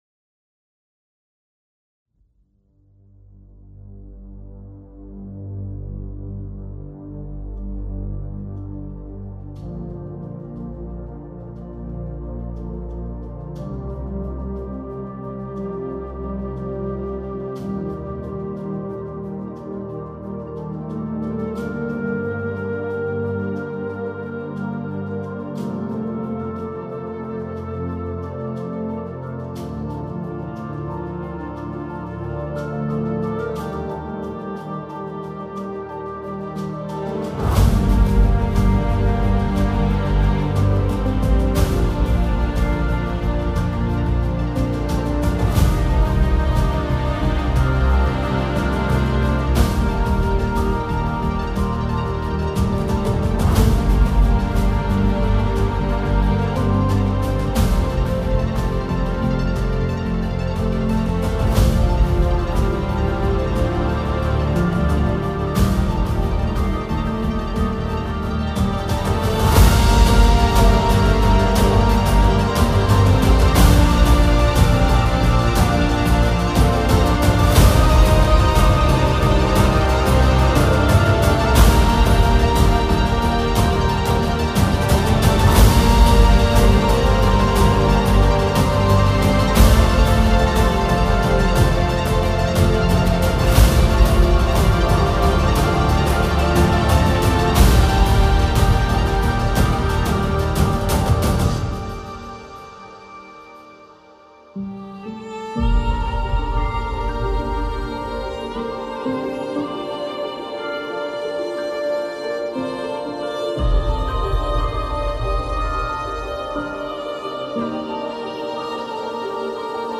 (بی کلام)
موسیقی حماسی موسیقی بیکلام